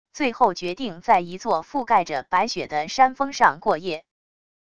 最后决定在一座覆盖着白雪的山峰上过夜wav音频生成系统WAV Audio Player